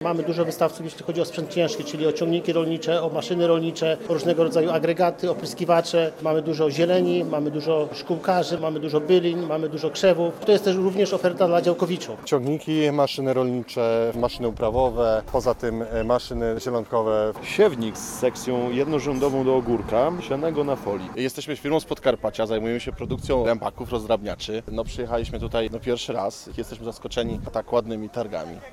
Na dwudniowych XXVI Targach Rolnych prezentuje się ponad 200 wystawców. Wiceminister rolnictwa Ryszard Zarudzki podczas targów powiedział, że profilaktyka to podstawowe narzędzie w walce z rozprzestrzenianiem się wirusa afrykańskiego pomoru świń w Polsce.